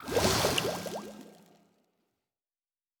pgs/Assets/Audio/Fantasy Interface Sounds/Potion and Alchemy 15.wav at master
Potion and Alchemy 15.wav